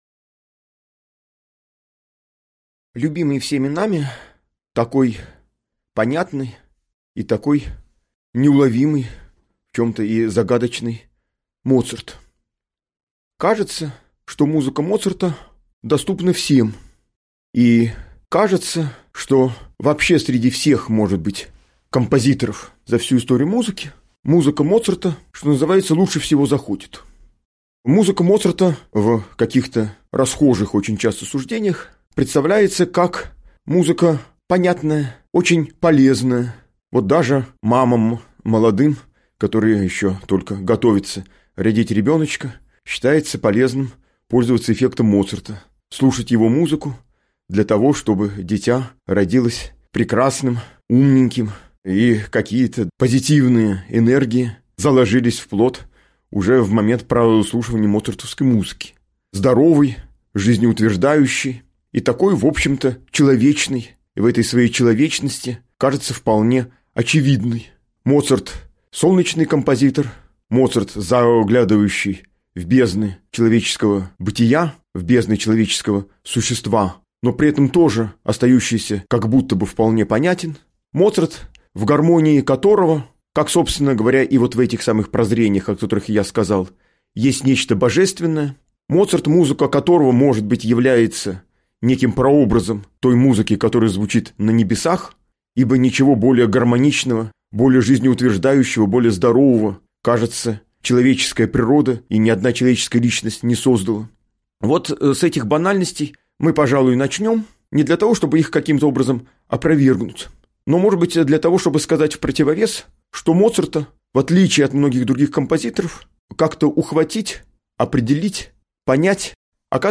Аудиокнига Лекция «Ускользающий Амадеус» | Библиотека аудиокниг